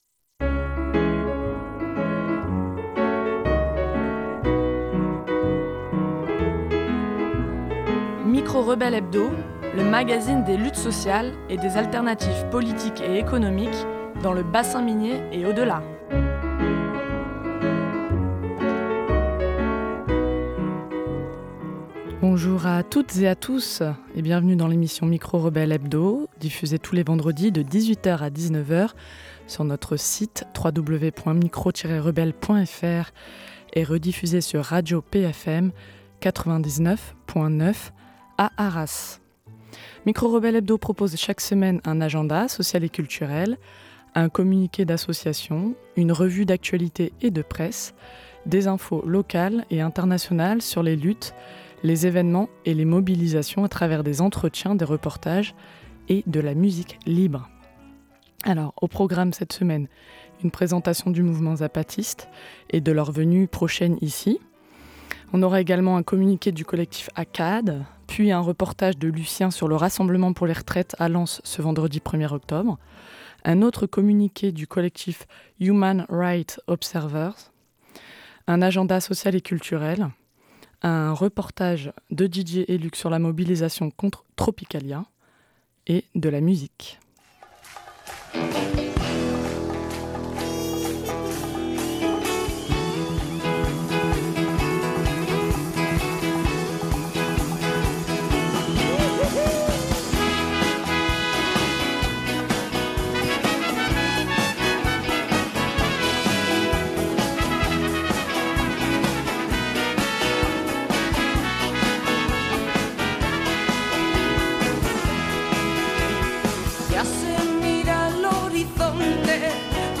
Notre studio est ouvert à toutes et tous chaque vendredi de 18h à 19h pour assister ou participer à l’émission Micros-rebelles hebdo !